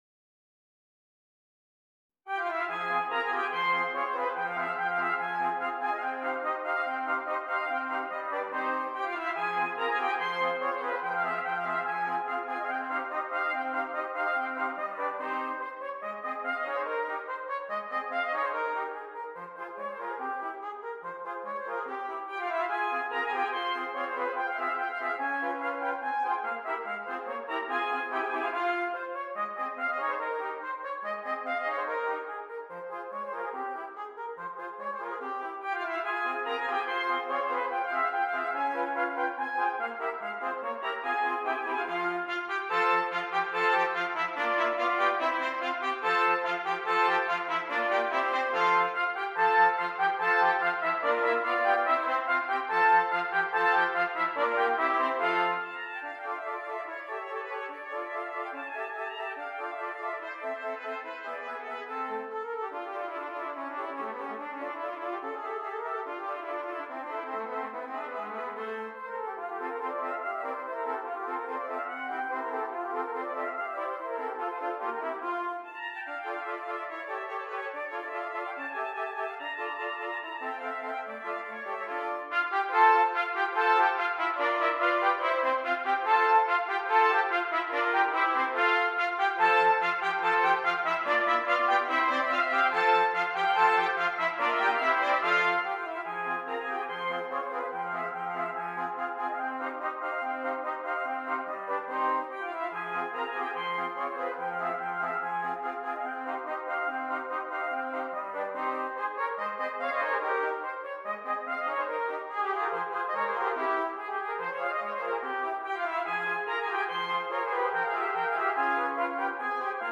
6 Trumpets
Difficulty: Medium-Difficult Order Code